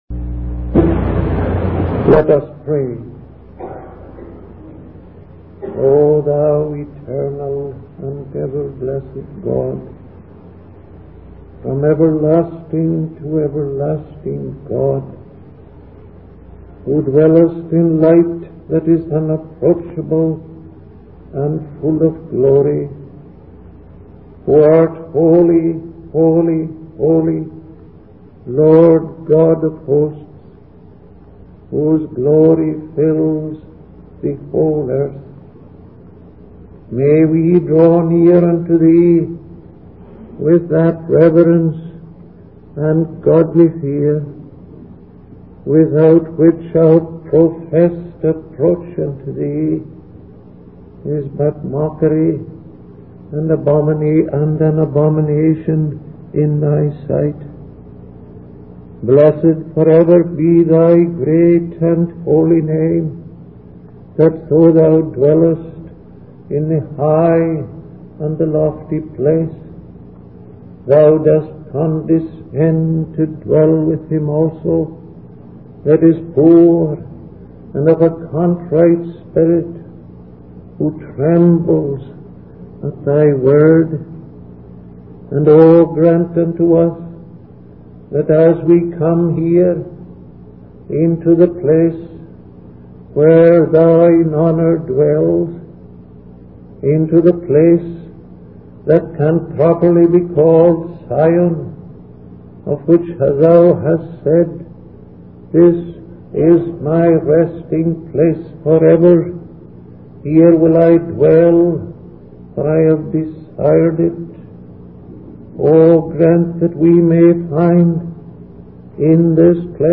In this sermon, the preacher focuses on the contrasting reactions of Peter and Jesus to different situations.